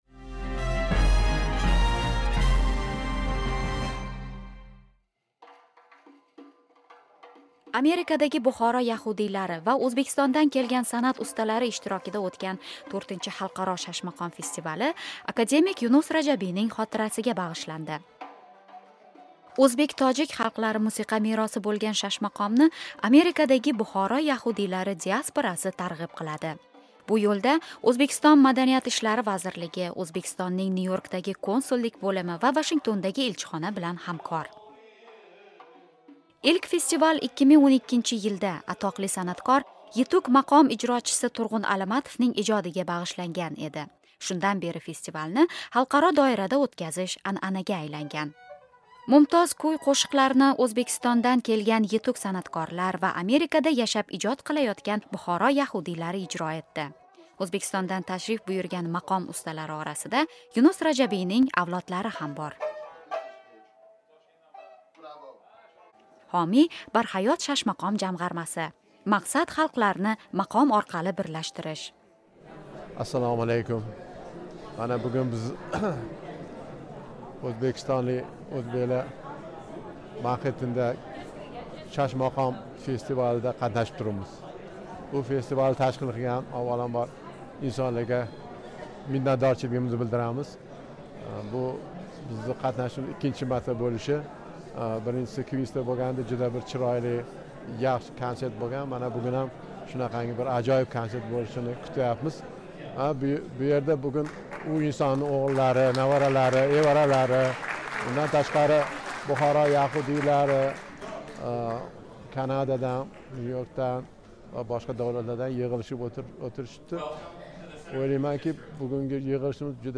Amerikadagi Buxoro yahuydiylari va o’zbekistondan kelgan san’at ustalari ishtirokida o’tgan IV Xalqaro shashmaqom festivali akademik Yunus Rajabiyning xotirasiga bag’ishlandi.